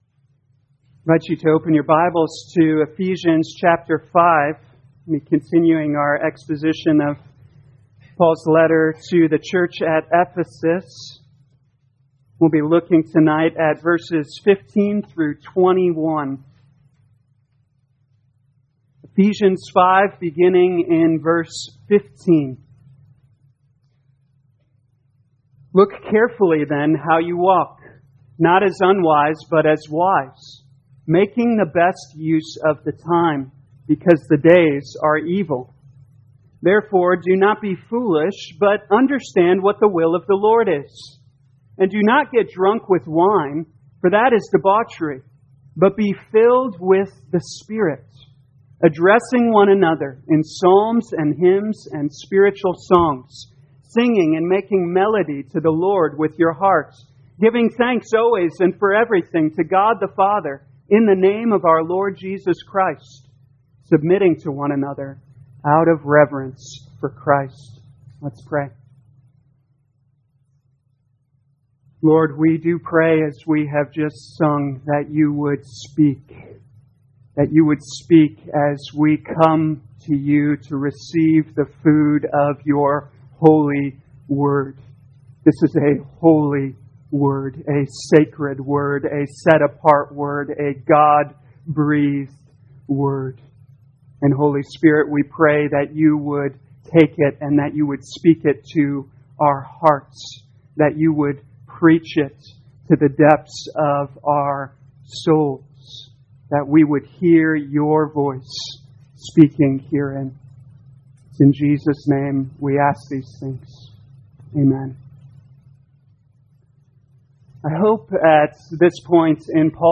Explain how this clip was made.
2021 Ephesians Evening Service Download